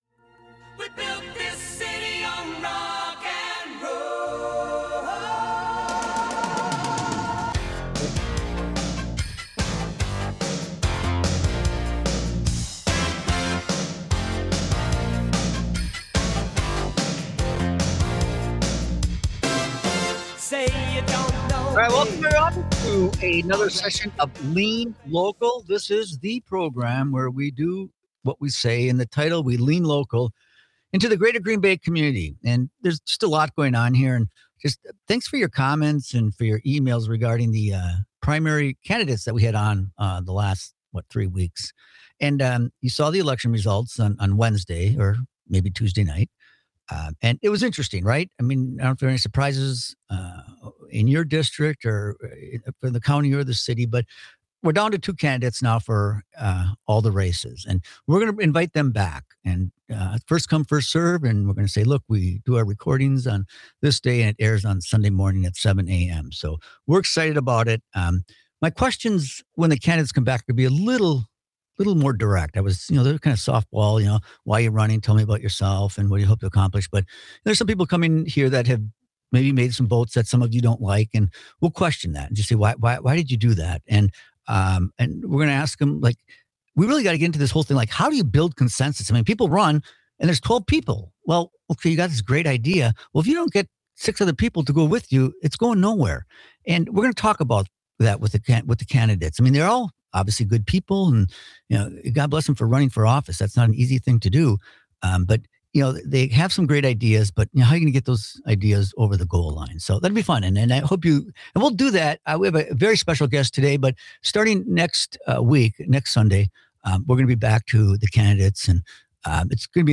Dive into the heart of community issues with 'Lean Local,' hosted by former Green Bay Mayor Jim Schmitt.
Instead, it 'leans local' with insightful discussions and grassroots solutions, focusing on what truly matters in our neighborhoods and communities.